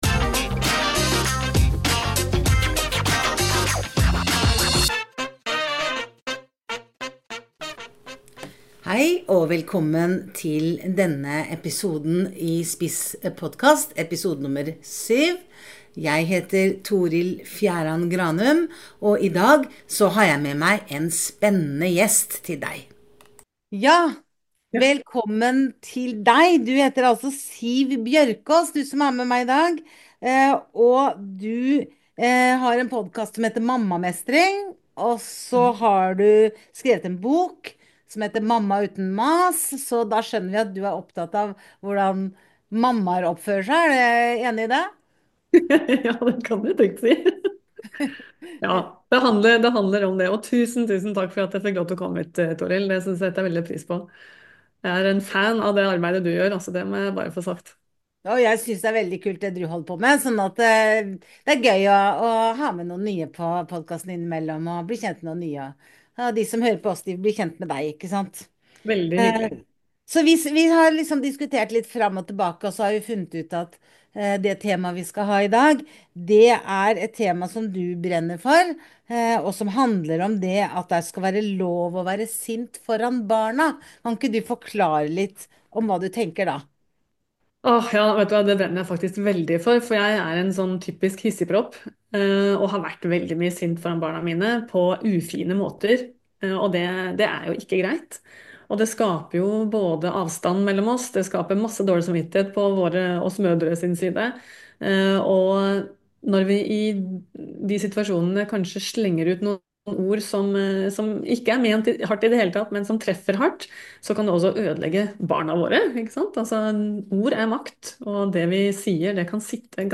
en samtale